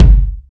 KICK.99.NEPT.wav